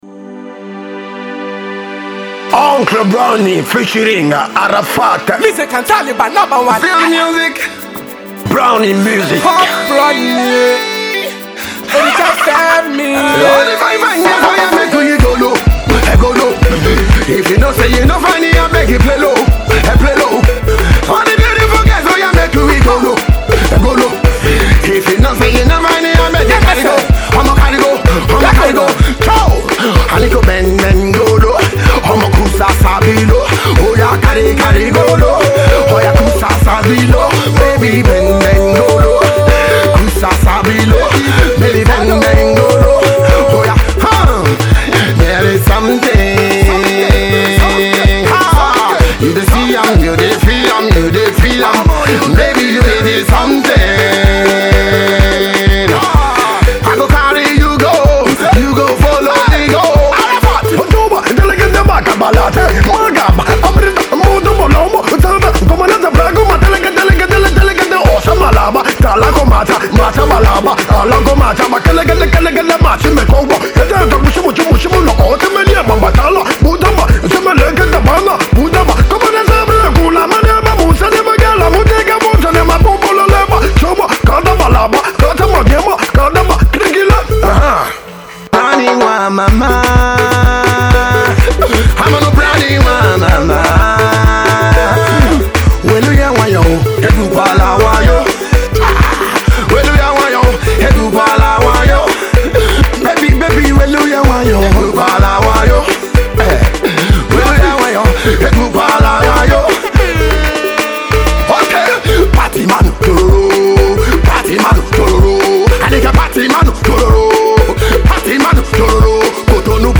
Pop
Party Song